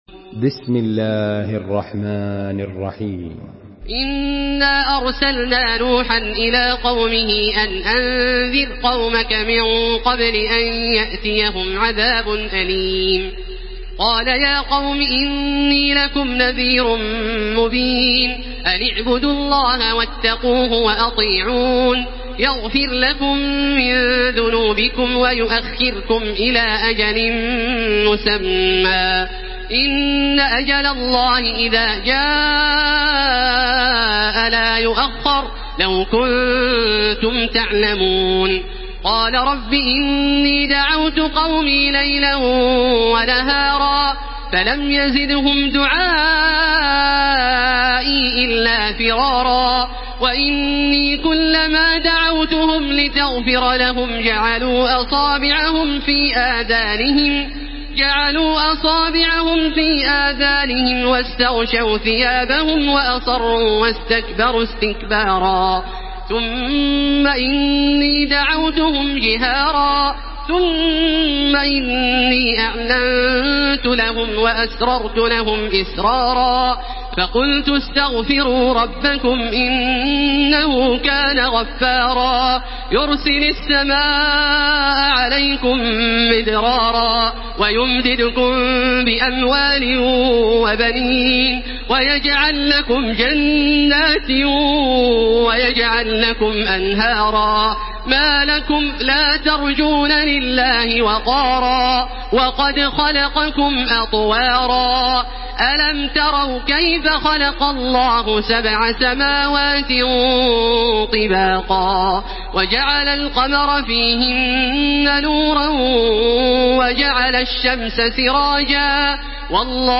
سورة نوح MP3 بصوت تراويح الحرم المكي 1434 برواية حفص
مرتل